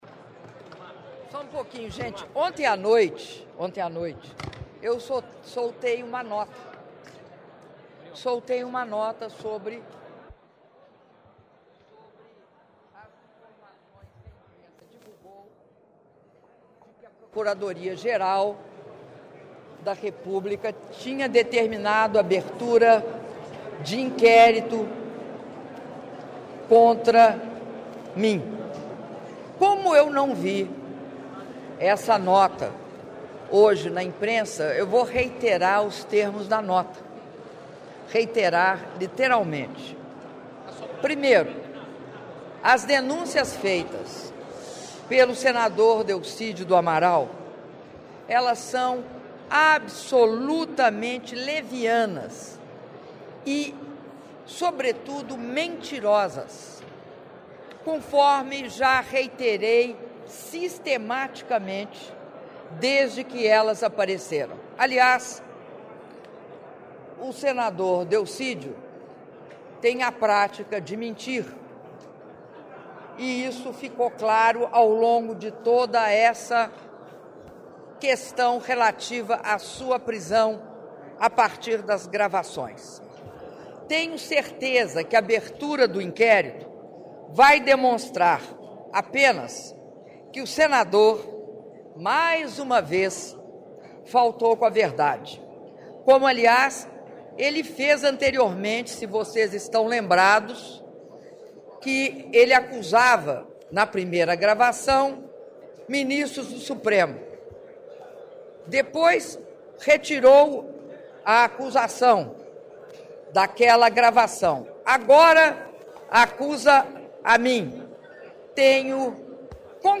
Áudio da Entrevista da Presidenta da República, Dilma Rousseff, após cerimônia de Lançamento do Plano Agrícola e Pecuário 2016/2017 - Brasília/DF (03min58s)